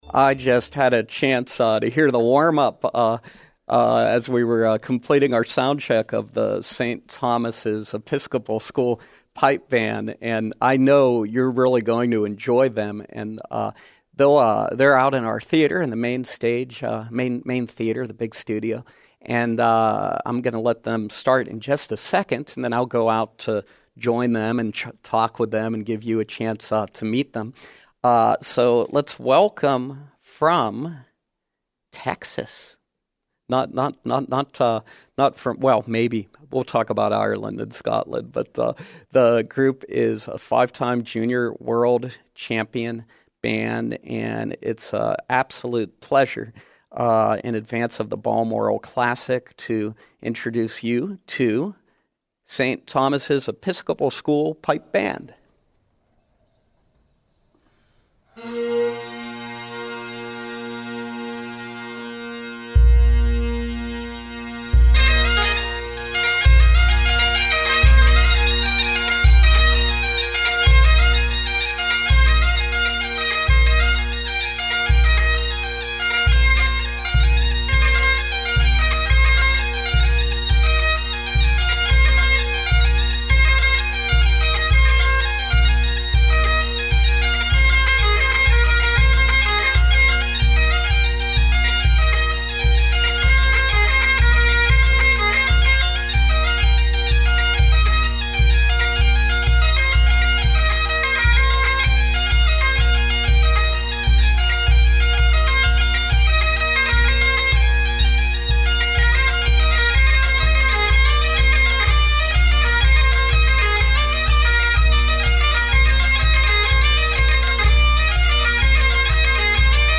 In anticipation of their Balmoral Classic appearance, the award winning St. Thomas’ Episcopal School Pipe Band joins us for a live radio performance.
This five-time Junior World Champion band electrifies the audience with traditional and contemporary music performed by pipers and drummers, supplemented with keyboard, percussion, fiddle, and guitar.